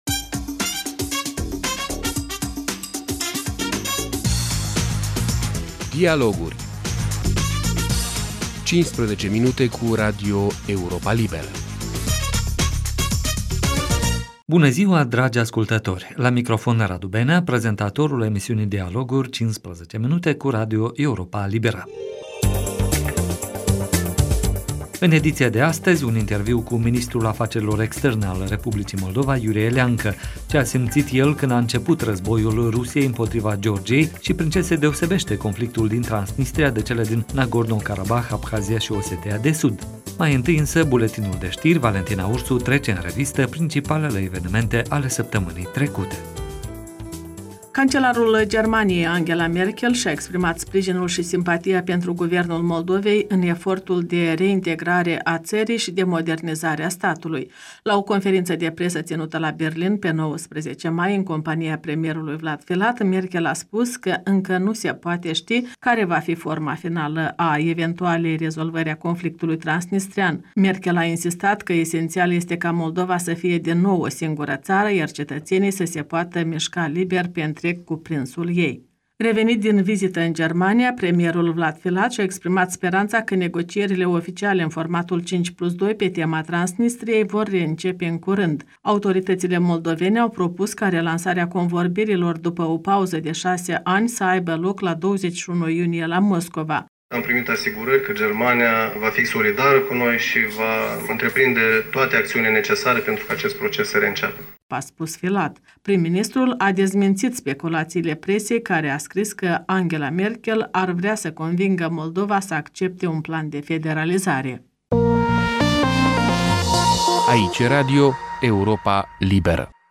În ediţia de astăzi – un interviu cu ministrul afacerilor externe al Republicii Moldova, Iurie Leancă. Ce a simţit el când a început războiul Rusiei împotriva Georgiei şi prin ce se deosebeşte conflictul din Transnistria de cele din Nagorno-Karabah, Abhazia şi Osetia de Sud?